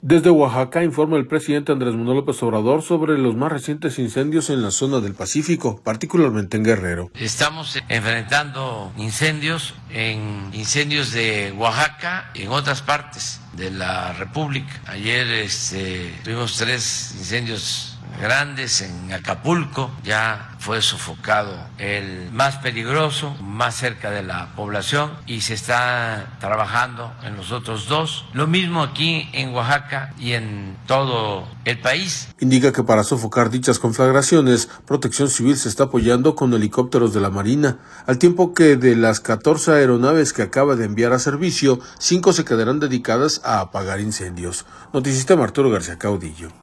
Desde Oaxaca, informa el presidente Andrés Manuel López Obrador sobre los más recientes incendios en la zona del Pacífico, particularmente en Guerrero.